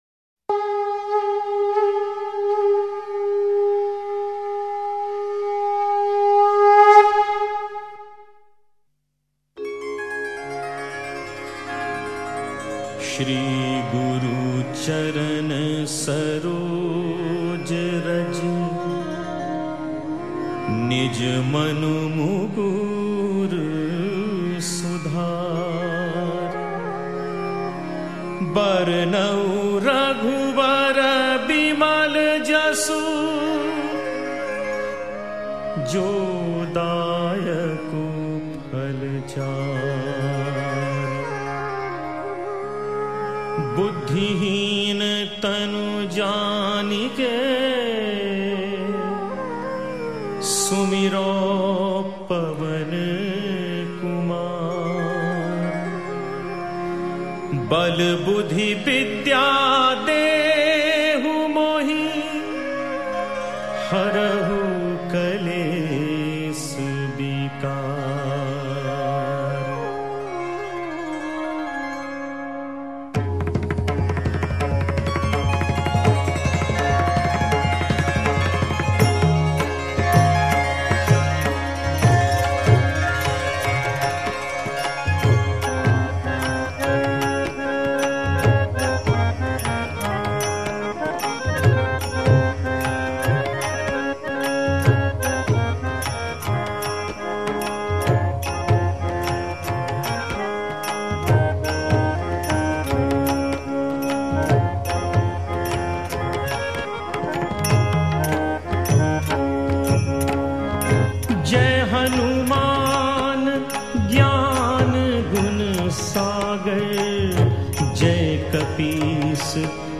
Devotional Songs > Shree Hanuman Bhajans